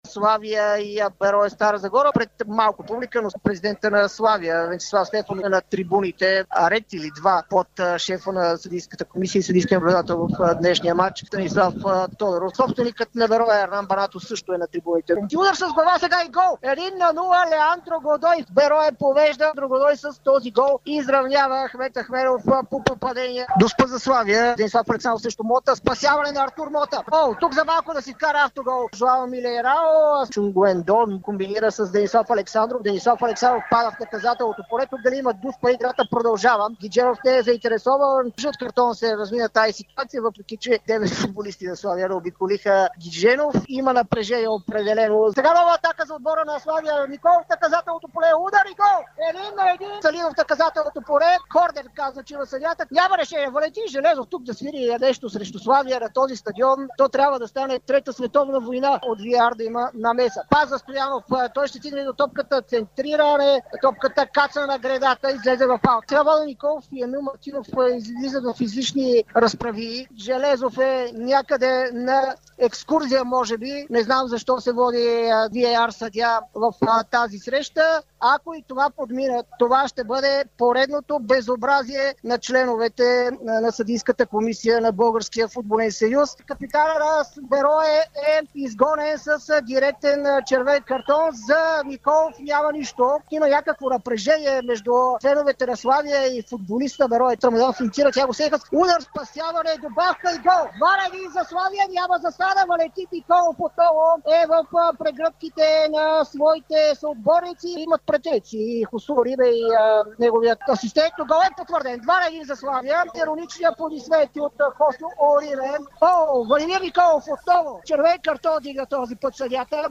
Какво чухме по Дарик от родните терени от последния преди паузата за националите отбори 25-и кръг в Първа лига с разгромите, ремитата и картоните през очите на коментаторите на Дарик радио!